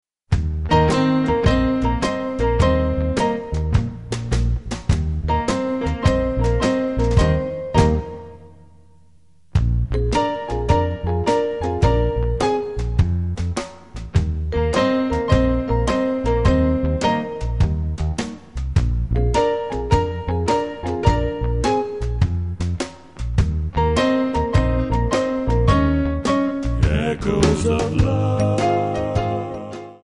Backing track files: 1960s (842)
Buy With Backing Vocals.